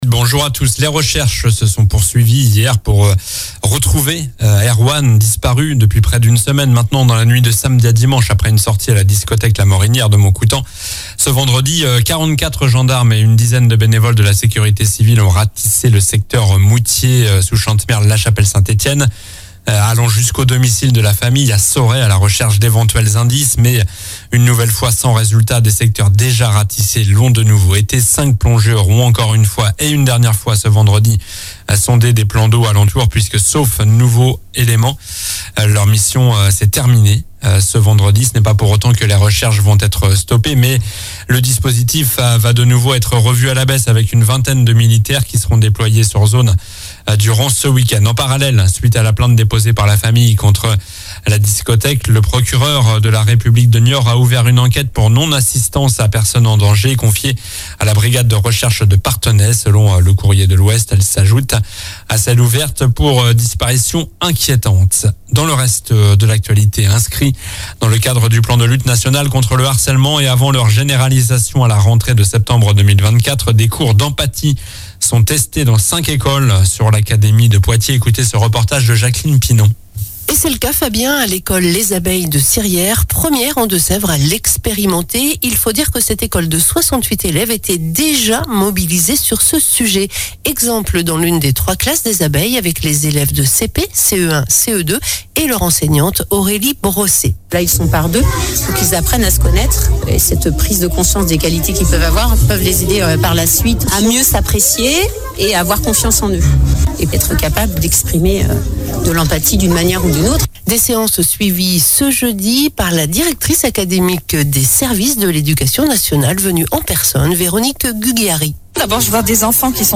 Journal du samedi 17 février (matin)